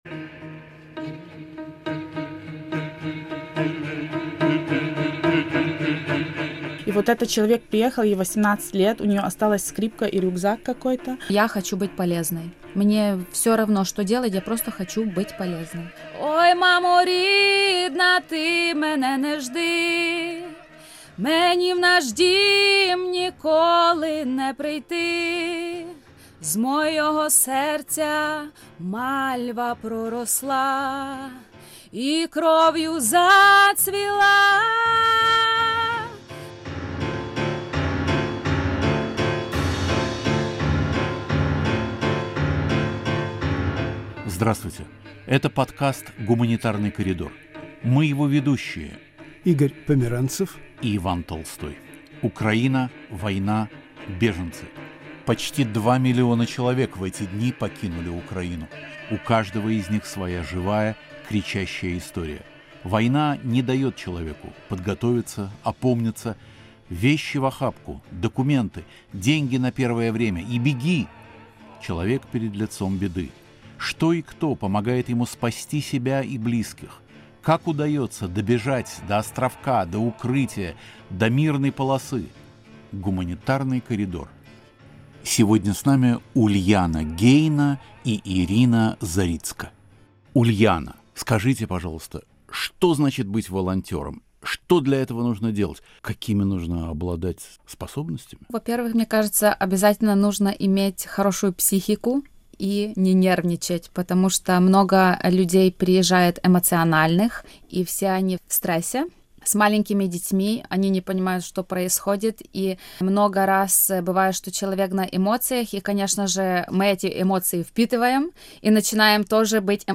В студии "Свободы"